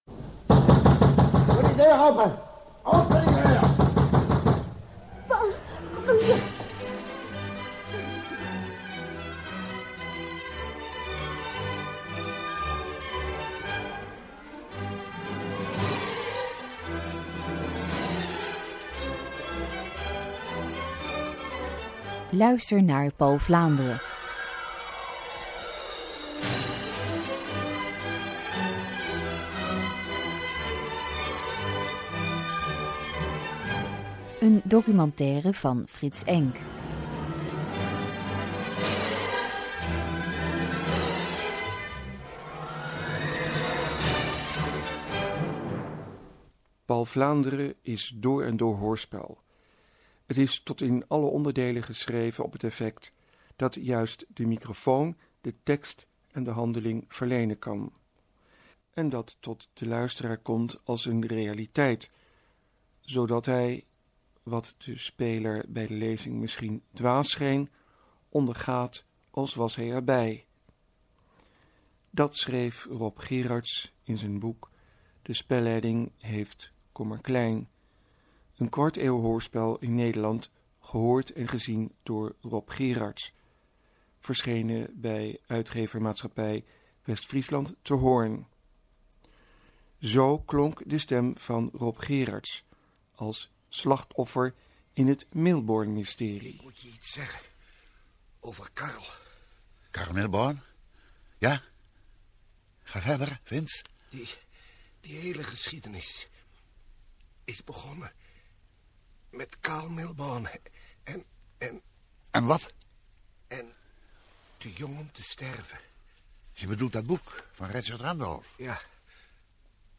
Aangepaste-documentaire-Paul-Vlaanderen.mp3